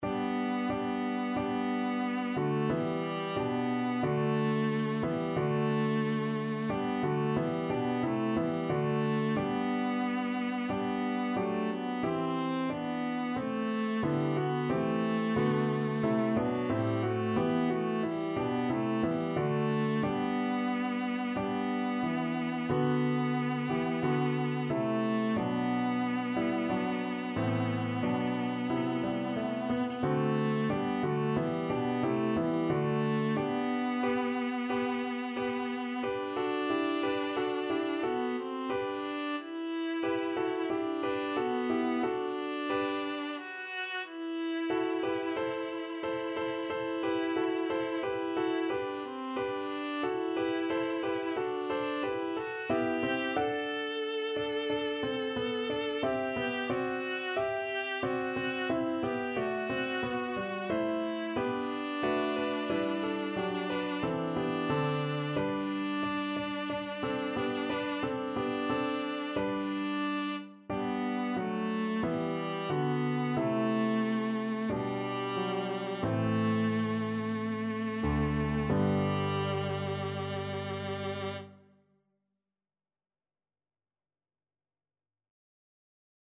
4/4 (View more 4/4 Music)
Classical (View more Classical Viola Music)